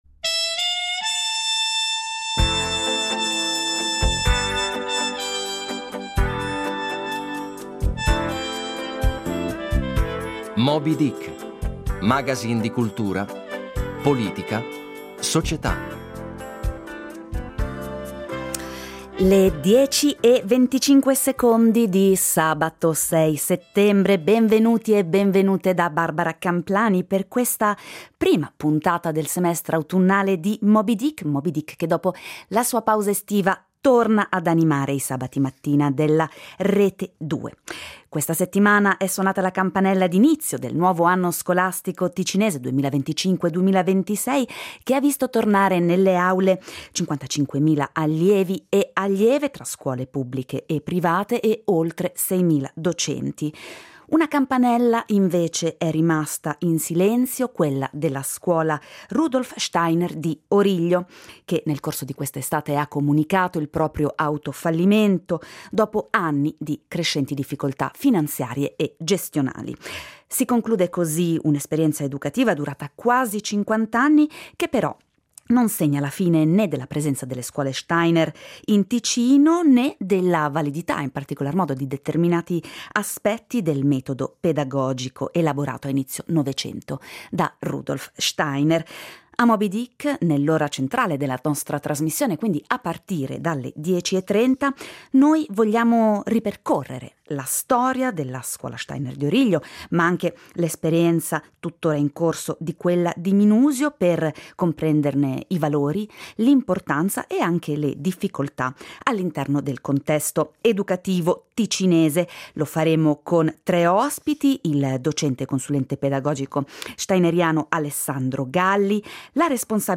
In apertura di puntata un’intervista alla Direttrice del DECS Marina Carobbio sulle sfide e sulle priorità della scuola ticinese per l’anno 2025-26.